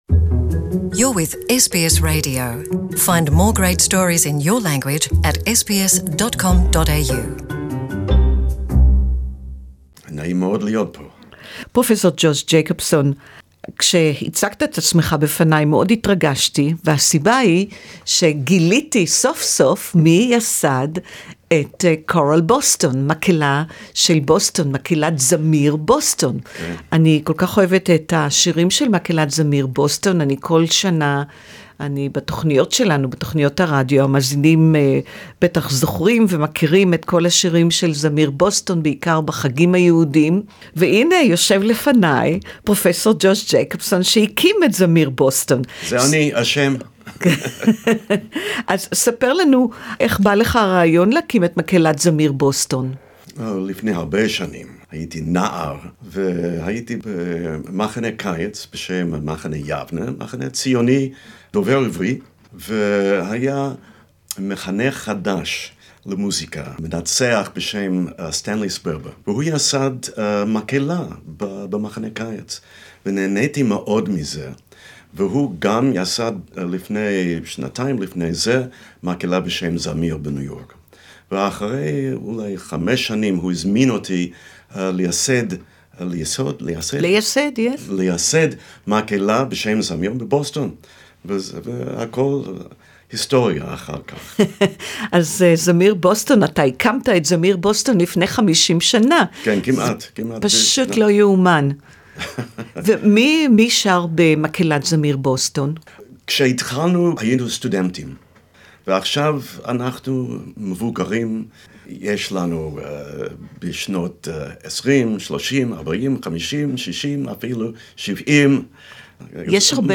interview in Hebrew